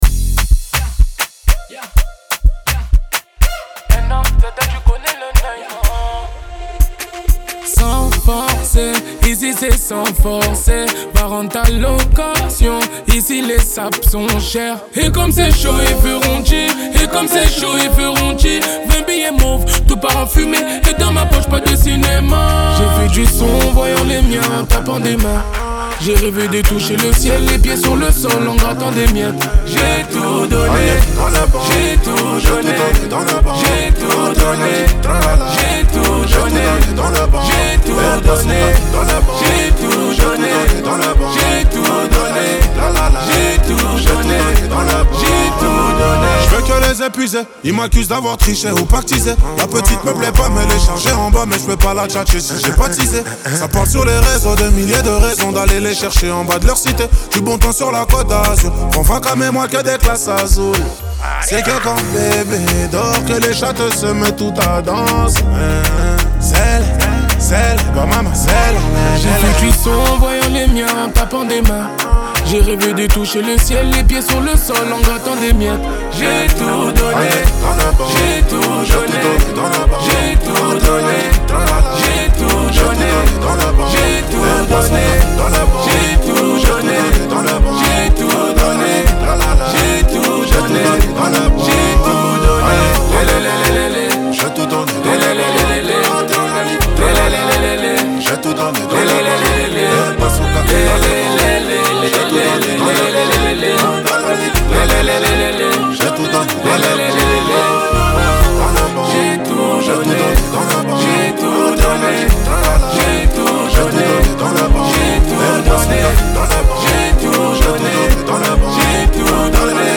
Genres : pop urbaine, french rap, french r&b